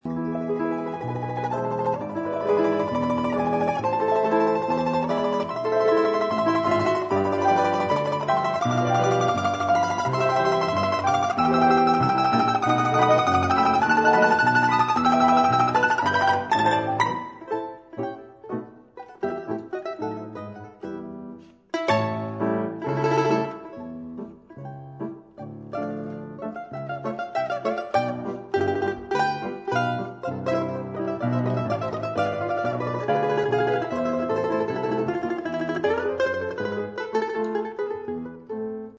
Занятие шестое. Струнные инструменты
domra.mp3